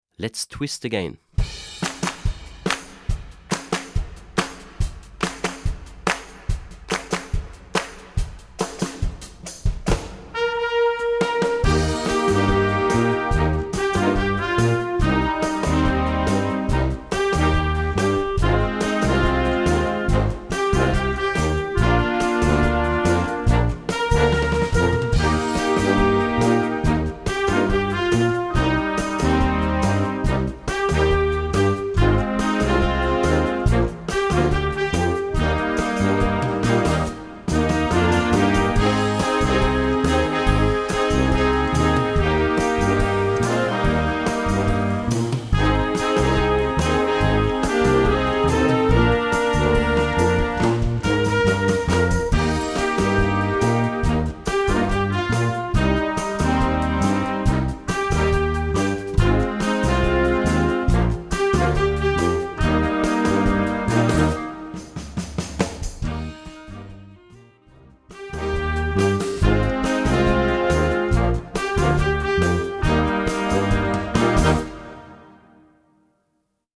Gattung: Jugendmusik
Besetzung: Blasorchester
Bläserensemble, von 4 Spielern bis zum vollem Blasorchester.